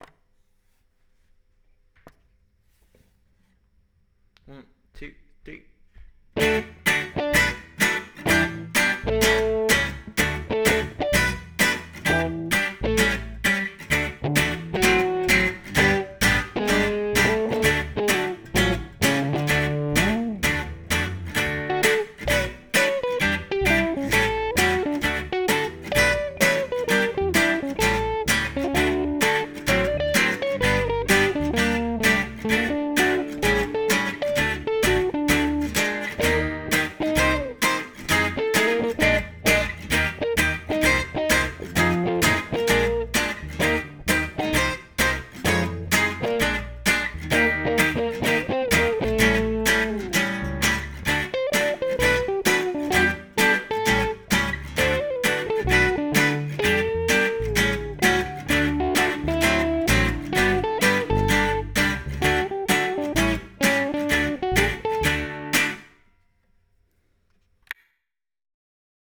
Recorded this demo. Everything is one take.
I used my 30s, Regal Prince archtop for rhythm. Recorded with the imbeded mics.
Mosrite ventures model bass with flats straight into the board. Used the tube preamp modeler as well.
I used my Smith special electric straight in using a tweed deluxe modeler.
I mastered it with some room reverb and a lo Fi sound.